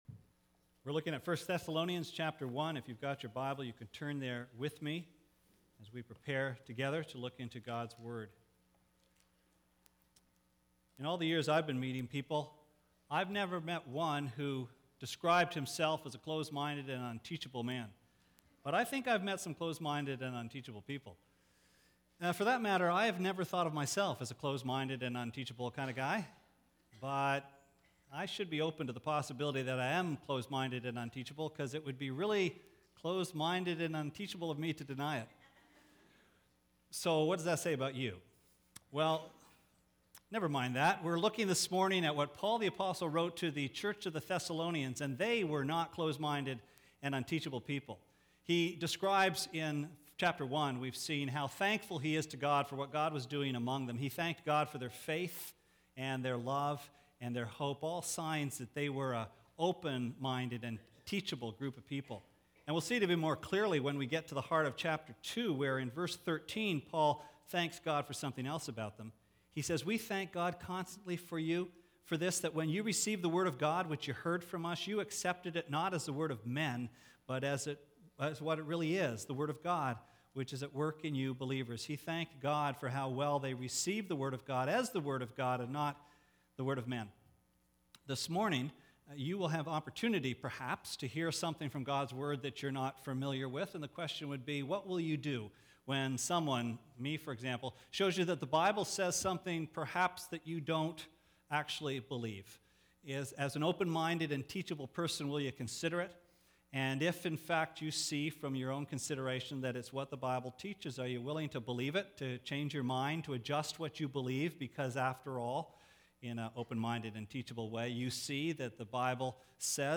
Sermon Archives Feb 4, 2007 - CTW 5: The Wrath to Come?